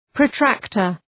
Προφορά
{prə’træktər}